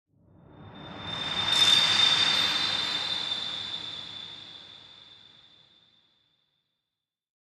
Minecraft Version Minecraft Version latest Latest Release | Latest Snapshot latest / assets / minecraft / sounds / ambient / nether / nether_wastes / mood3.ogg Compare With Compare With Latest Release | Latest Snapshot